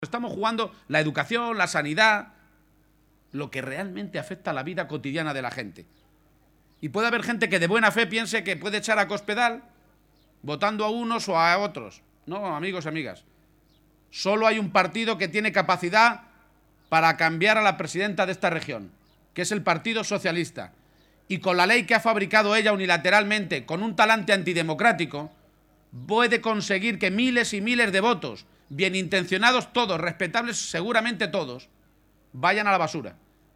En una intervención previa a una comida con militantes y simpatizantes en Consuegra (Toledo), García-Page ha señalado que puede haber muchas personas que, de buena fe, piensen que pueden echar a Cospedal votando a unos u a otras formaciones políticas, “cuando la verdad es que solo hay un partido, el PSOE, que tiene capacidad para cambiar la presidencia de Castilla-La Mancha y más con la ley trampa que han fabricado y que podría provocar que miles de votos se fueran a la basura”.